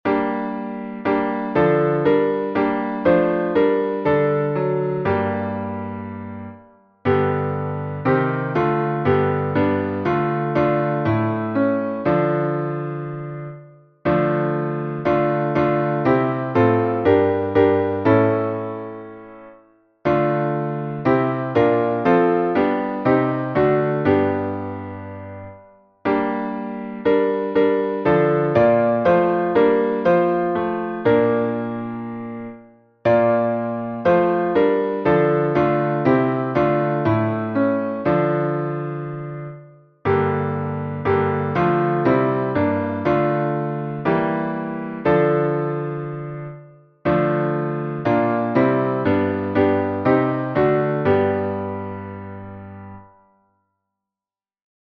Harmonização: Claude Goudimel, 1564
salmo_7A_instrumental.mp3